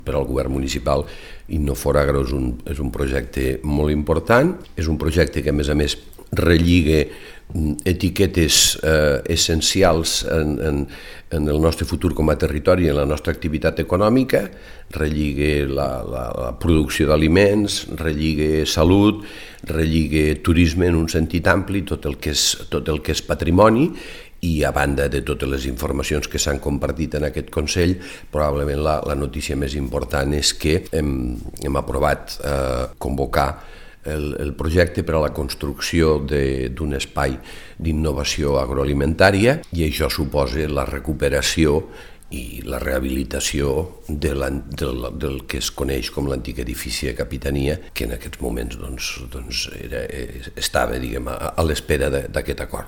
tall-de-veu-alcalde-de-lleida-miquel-pueyo-sobre-el-projecte-inno4agro-i-la-recuperacio-de-lantic-edifici-de-capitania